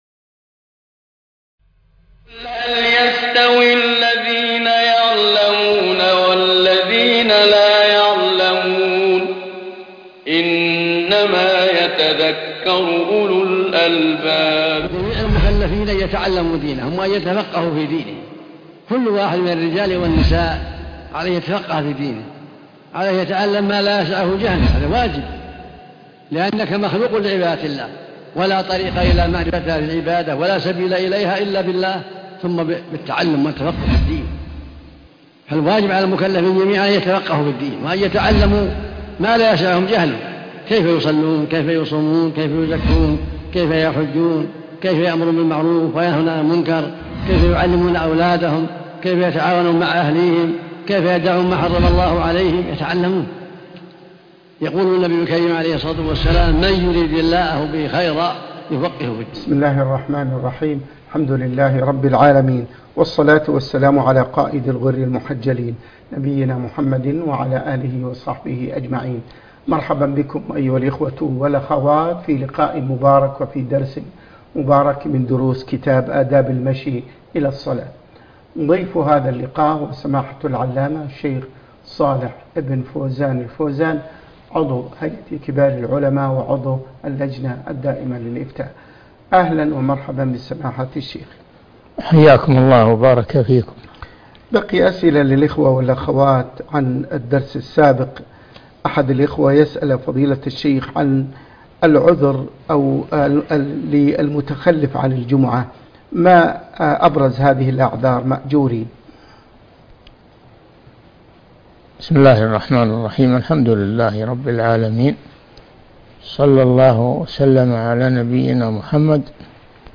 الدرس السادس (6) آداب المشى الى الصلاه (3) - الشيخ صالح بن فوزان الفوازان